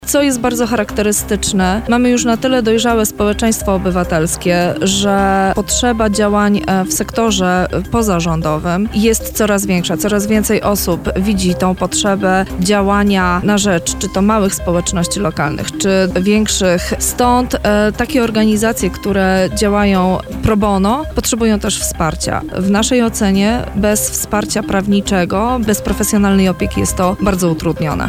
[PORANNA ROZMOWA] Lublin gospodarzem Forum „Prawnicy – NGO: Budujemy drogę do współpracy”